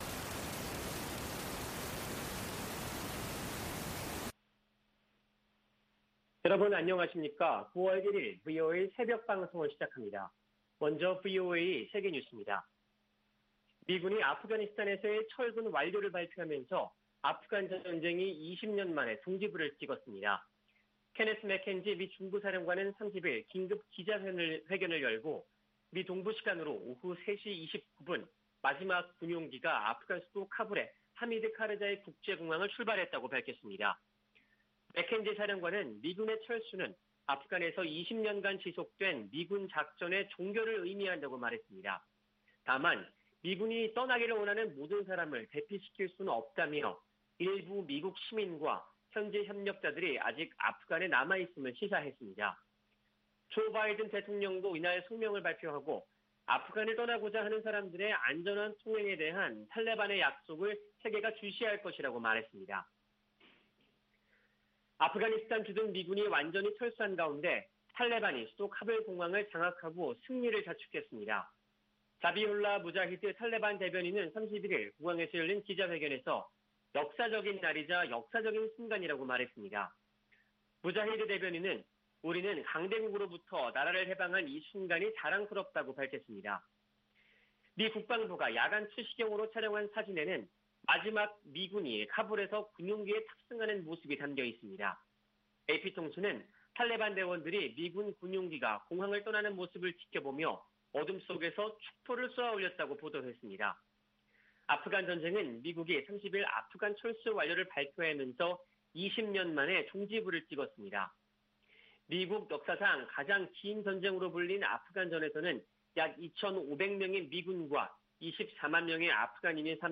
세계 뉴스와 함께 미국의 모든 것을 소개하는 '생방송 여기는 워싱턴입니다', 2021년 9월 1일 아침 방송입니다. '지구촌 오늘'에서는 미국이 마감 시한을 하루 앞두고 아프가니스탄에서 철군을 완료한 소식, '아메리카 나우'에서는 지난해 미국에서 12년 만에 가장 많은 혐오범죄가 발생한 소식 전해드립니다.